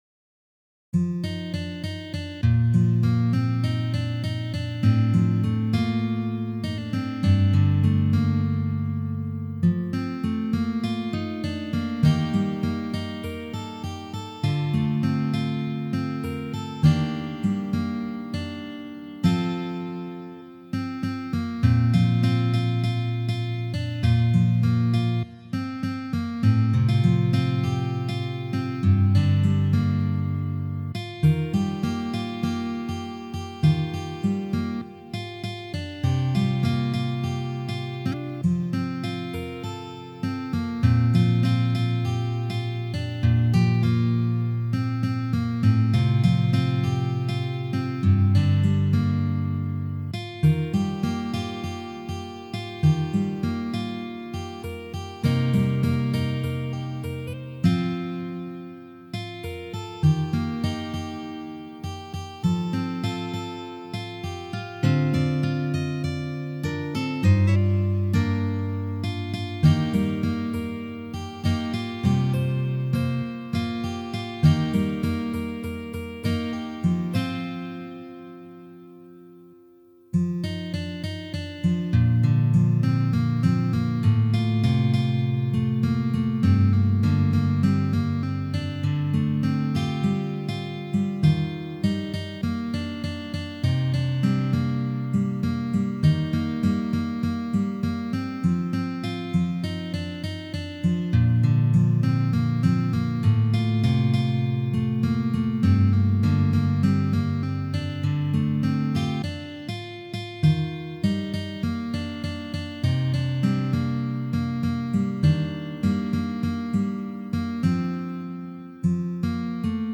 变调夹/指法：3品G调指法 曲谱类型：指弹谱
采用G调指法，难度适中，非常好听